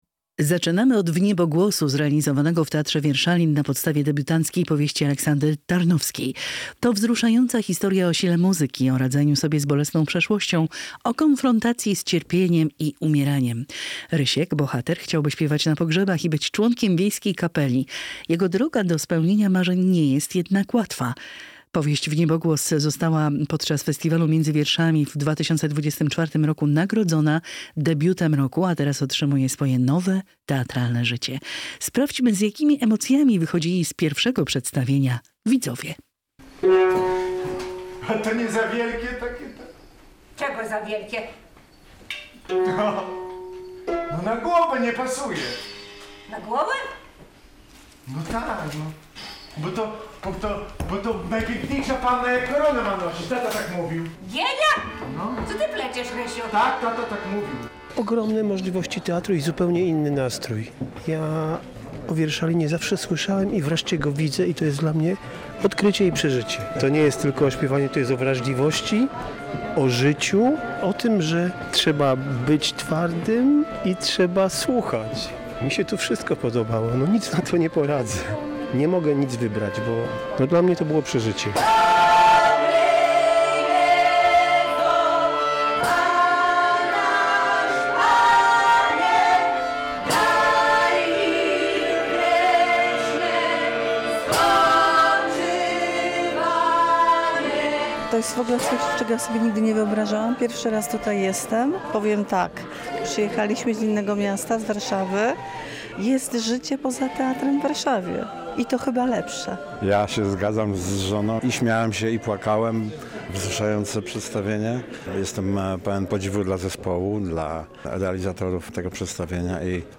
wrażenia widzów